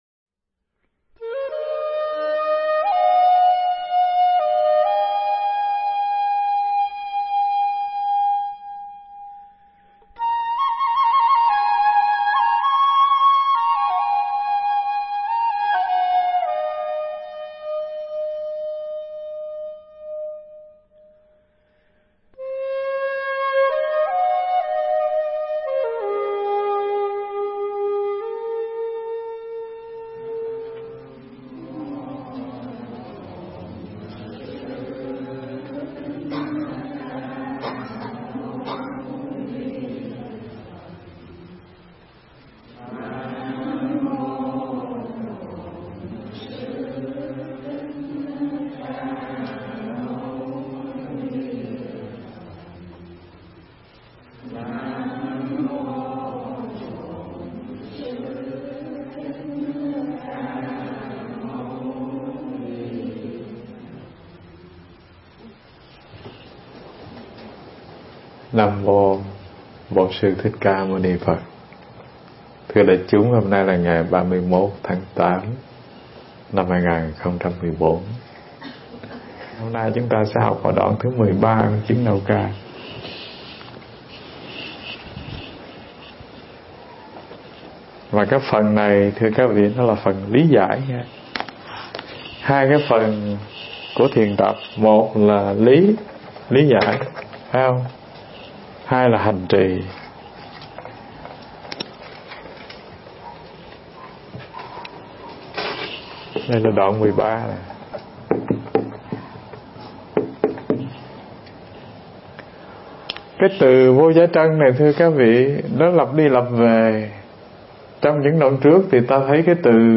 Mp3 Thuyết pháp Chứng Đạo Ca 15 Của Kho Vô Tận